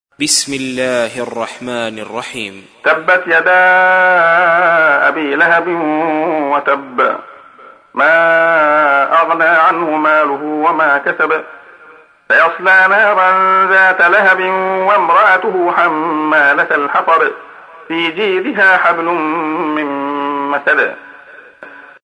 تحميل : 111. سورة المسد / القارئ عبد الله خياط / القرآن الكريم / موقع يا حسين